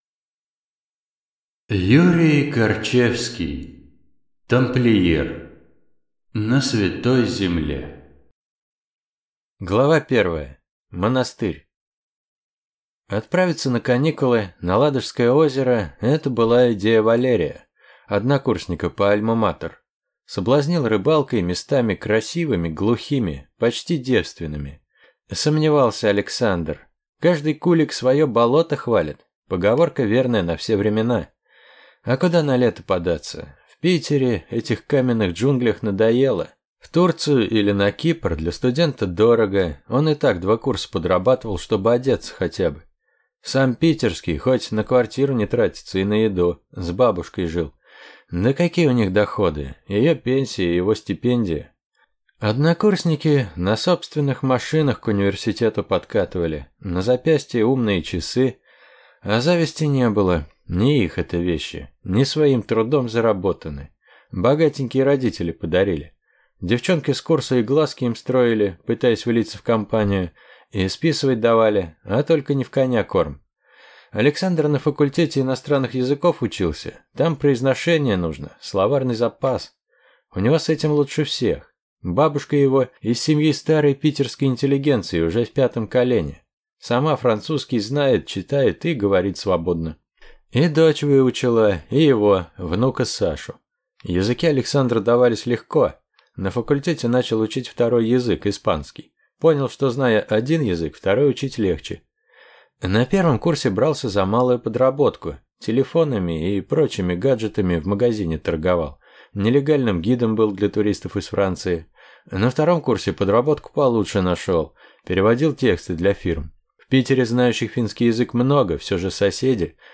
Аудиокнига Тамплиер. На Святой земле | Библиотека аудиокниг